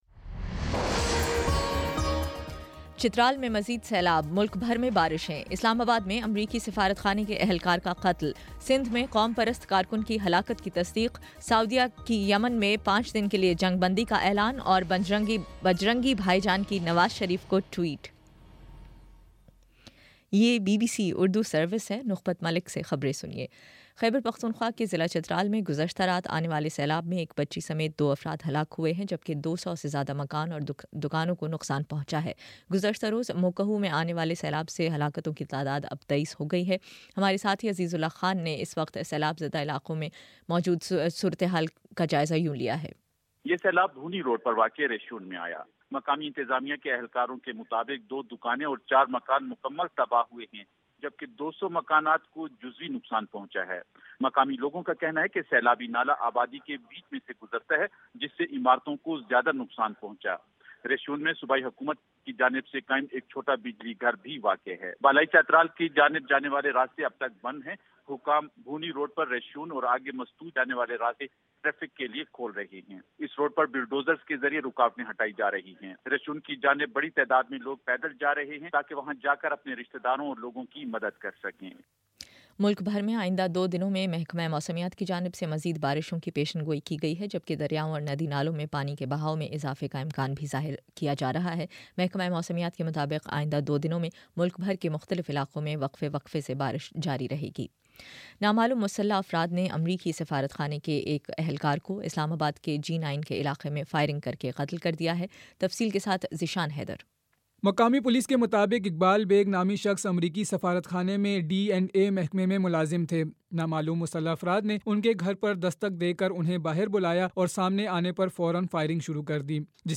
جولائی 26: شام سات بجے کا نیوز بُلیٹن